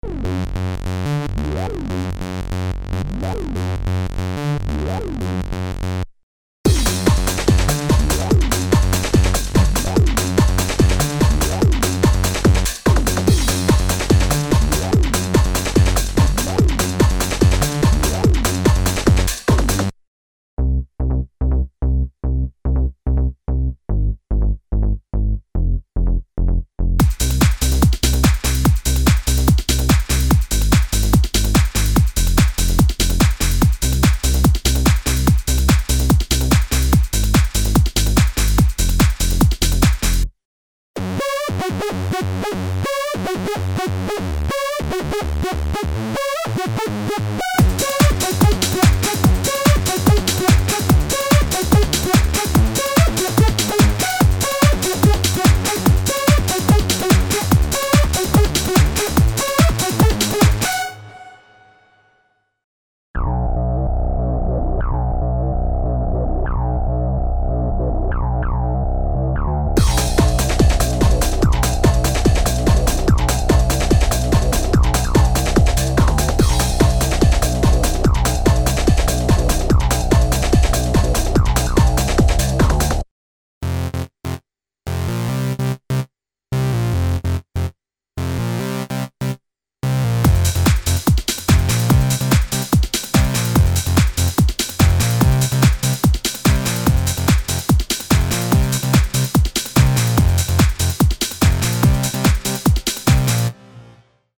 Special Techno collection containing essential soundware ranging from commercial radio techno sound to uncompromising "Detroit" underground sound (synth basses, seq. programs, chords and arpeggio grooves with BPM synchronized LFO).